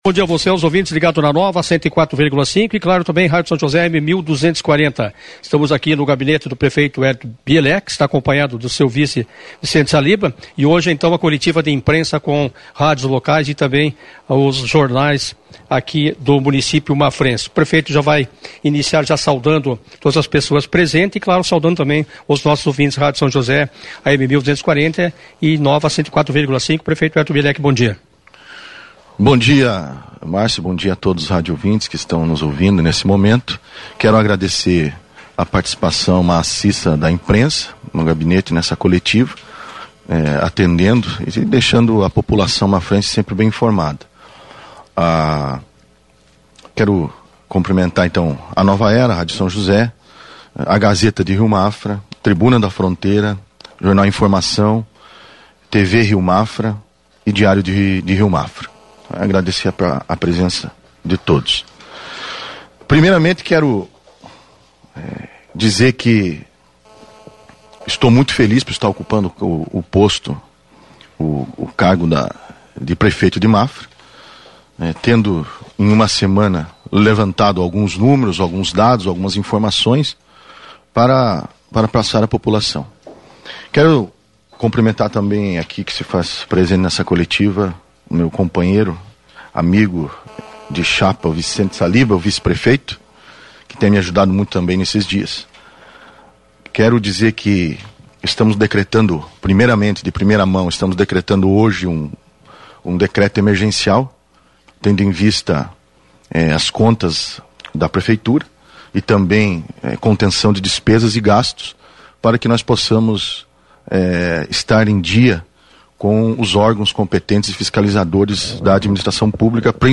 Ouça a gravação da entrevista coletiva do prefeito Wellington Bielecki | Click Riomafra
Na manhã desta sexta-feira (10), o prefeito Wellington Bielecki concedeu em seu gabinete, uma entrevista coletiva para a imprensa relatando toda a movimentação política e econômica atual do município de Mafra.